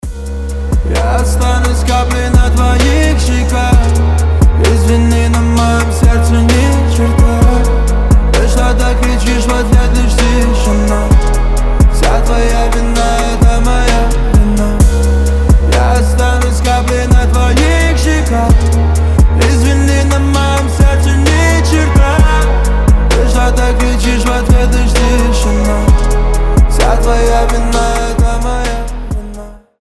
• Качество: 320, Stereo
лирика
грустные
спокойные